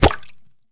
bullet_impact_water_02.wav